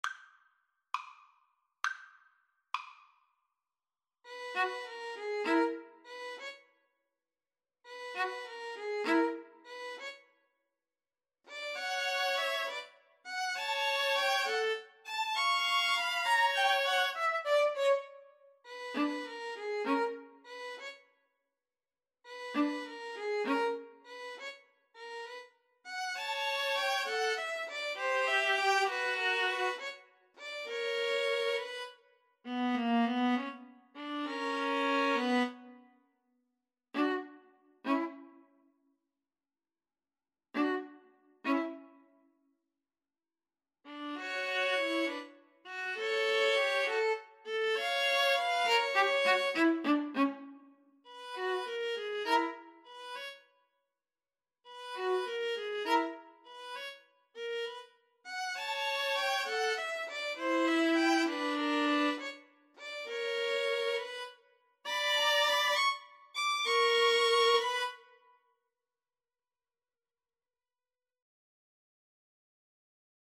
Moderato
6/8 (View more 6/8 Music)
Classical (View more Classical String trio Music)